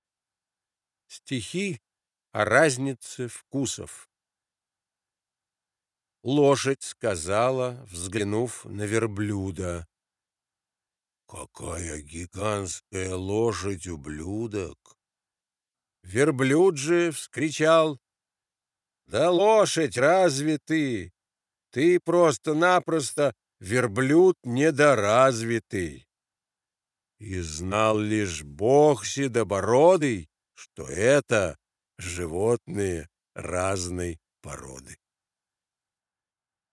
2. «Владимир Маяковский – Стихи о разнице вкусов (читает Вениамин Смехов)» /